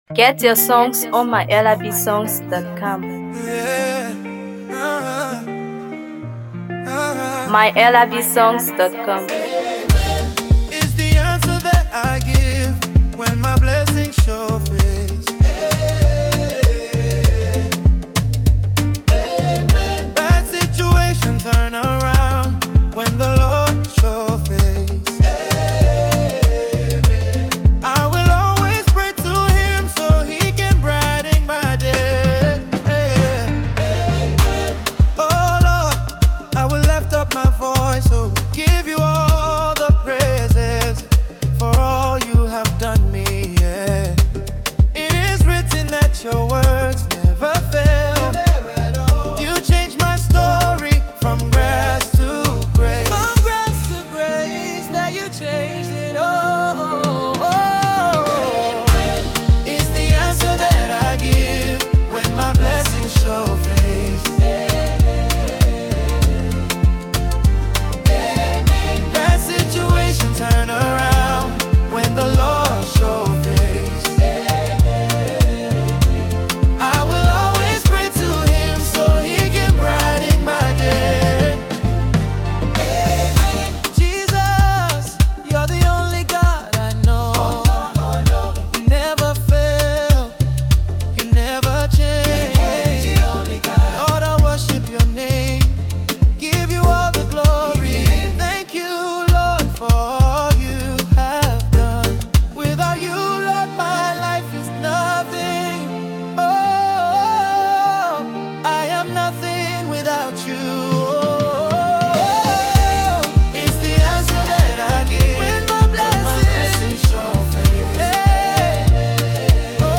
Afro PopGospel
inspirational gospel artist